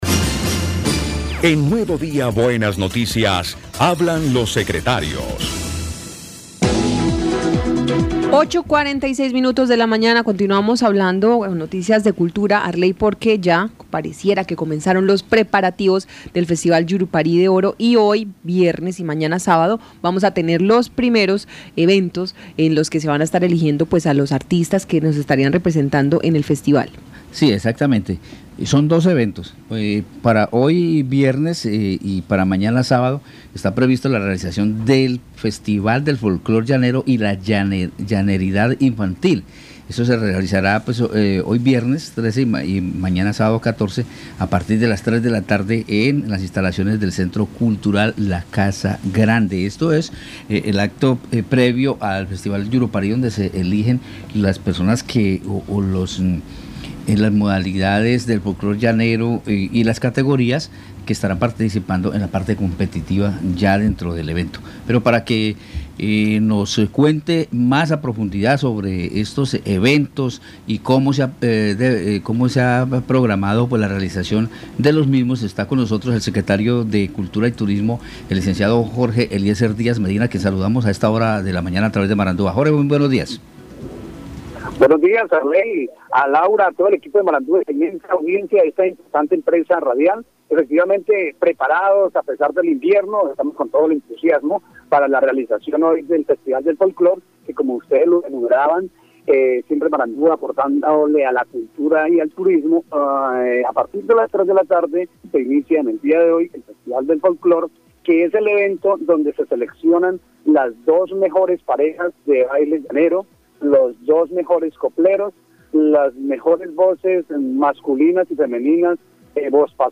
Escuche a Jorge Díaz, secretario de Cultura y Turismo del Guaviare.